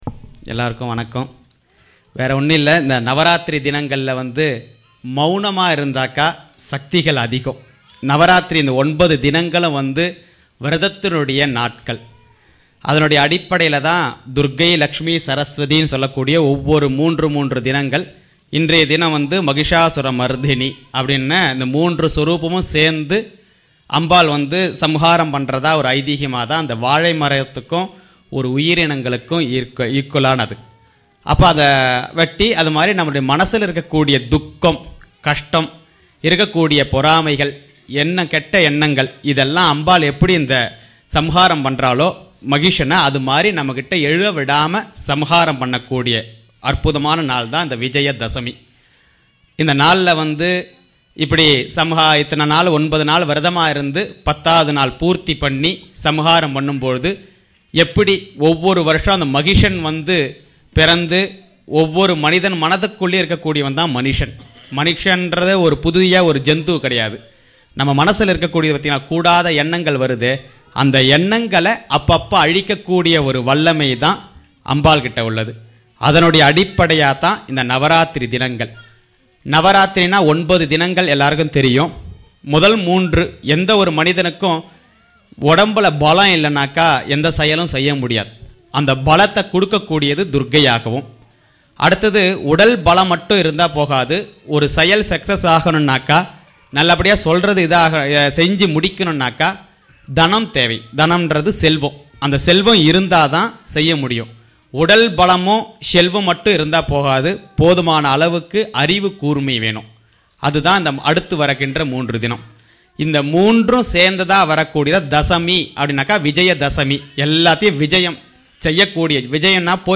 Speech
on Vijaya Dasami day